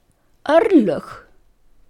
orlog.mp3